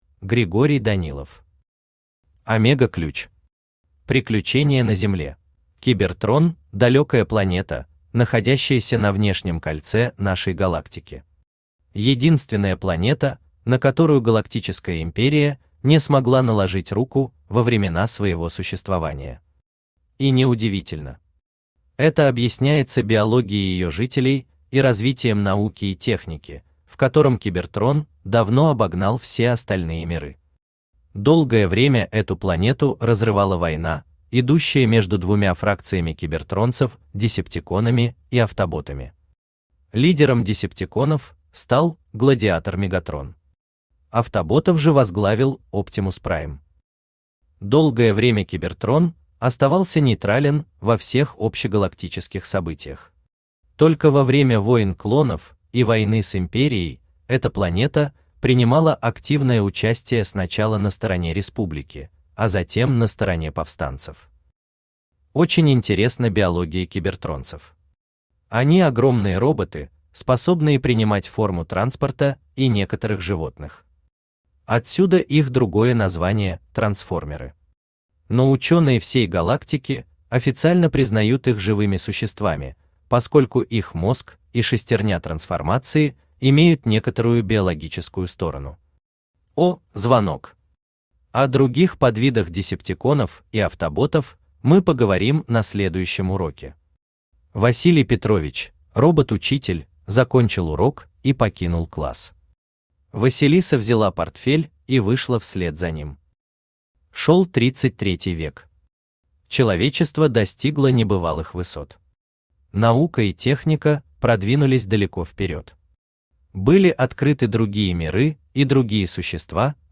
Приключения на Земле Автор Григорий Евгеньевич Данилов Читает аудиокнигу Авточтец ЛитРес.